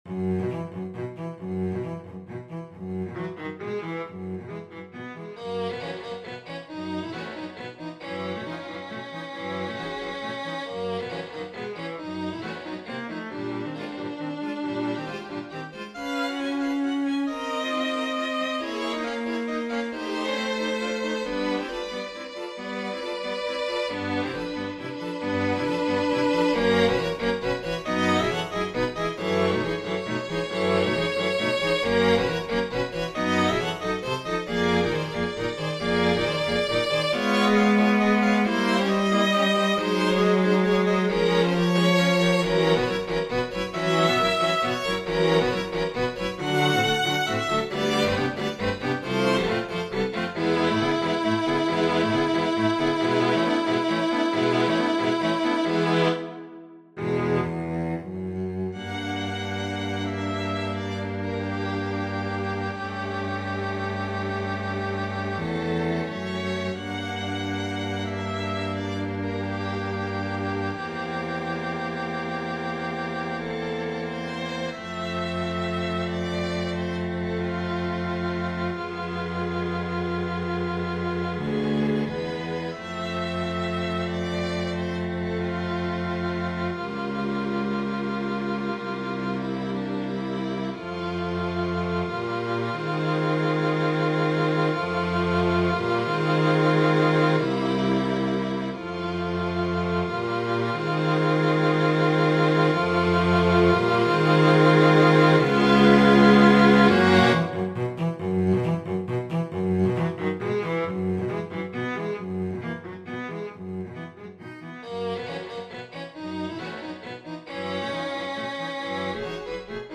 I'm attempting to write a string quartet piece, but I've hit a wall. I've written two sections that I like individually, but I'm having some trouble connecting them.